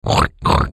Звуки хрюканья
Короткий вариант